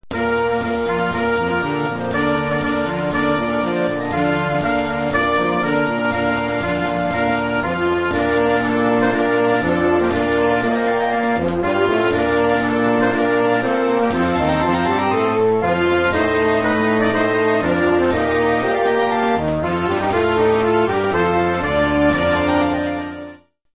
Collection : Harmonie (Marches)
Marche-parade pour harmonie-fanfare,
avec tambours et clairons ad lib.
Un pas-redoublé entraînant et facile